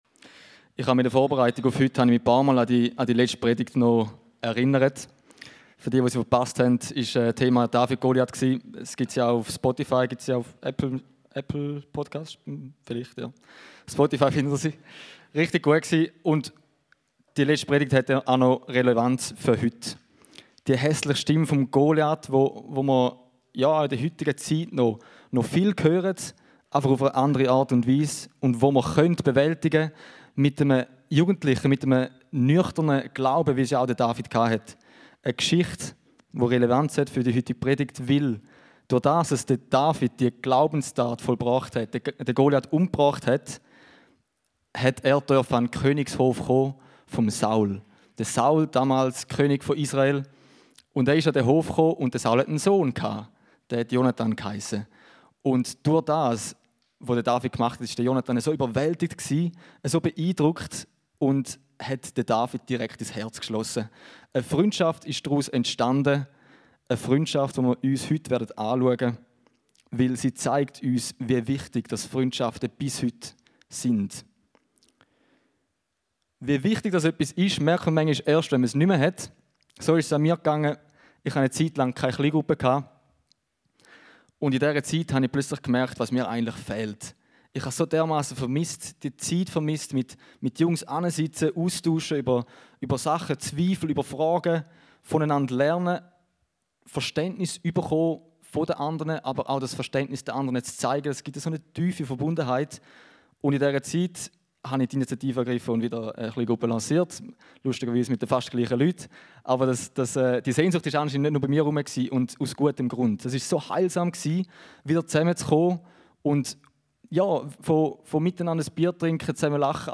Eine predigt aus der serie "RISE & FALL."